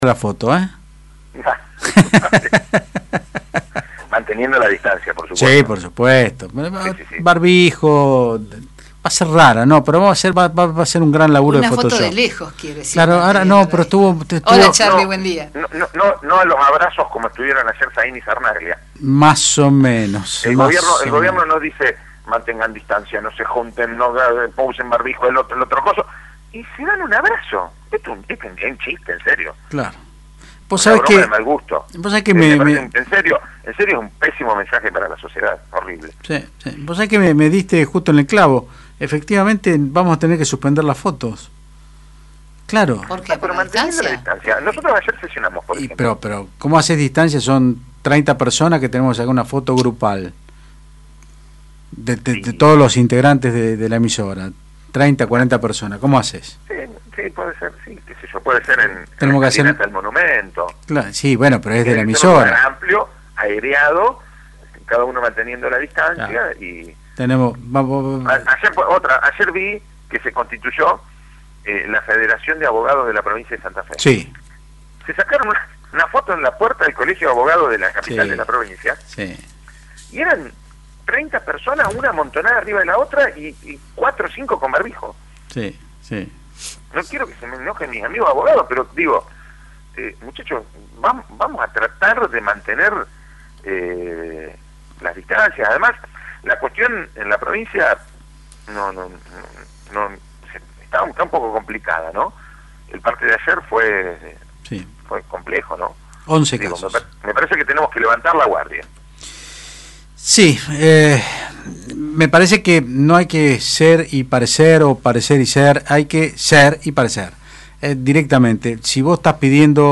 El concejal Charly Cardozo dijo en Otros Ámbitos (Del Plata Rosario 93.5) el Concejo Municipal de Rosario aprobó una iniciativa presentada por el edil Carlos Cardozo en la que se le encomienda al Departamento Ejecutivo Municipal que solicite a la Legislatura de la Provincia de Entre Ríos el tratamiento de carácter urgente de una norma que prohíba la quema de pastizales de cualquier tipo en la zona de las islas del Río Paraná, el pre Delta y toda la zona costera .